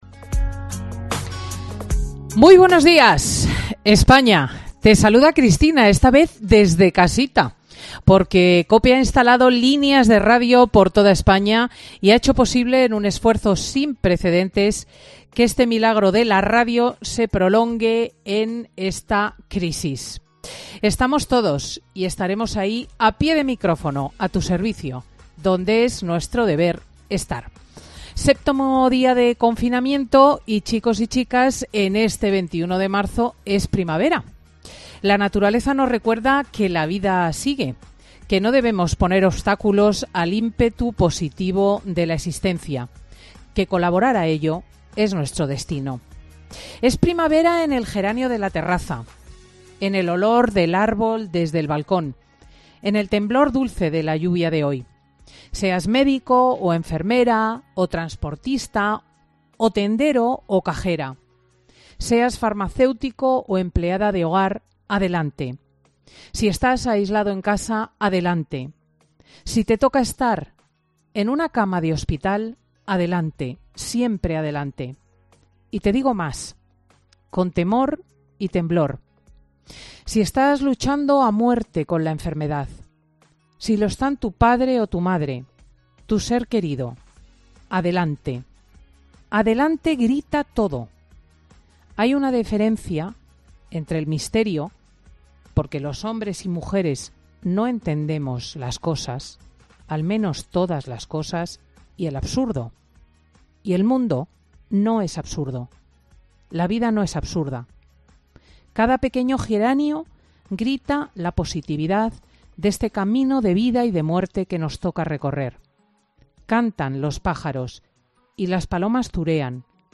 La directora y presentadora de Fin de Semana comenta la actualidad social y política del estado de alarma provocado por el coronavirus